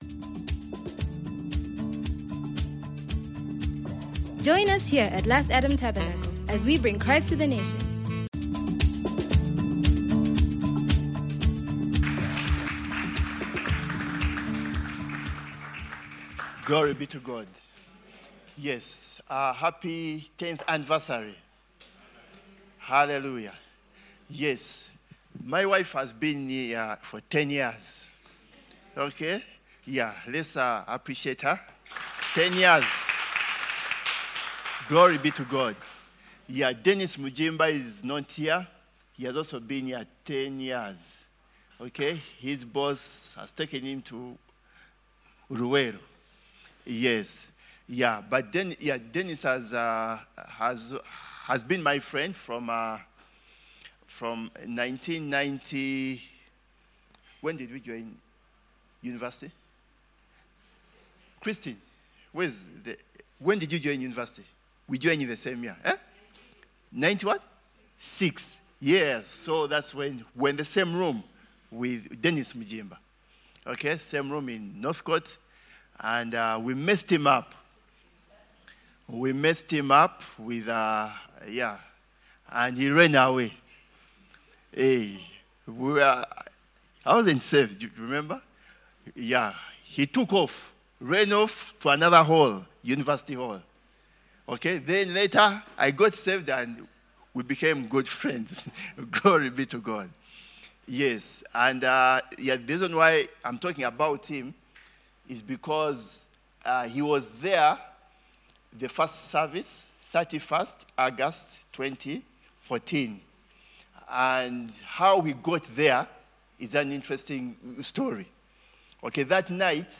LAT@10: Celebrating God's Approval of Our Church: Sermon Slides